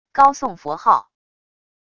高诵佛号wav音频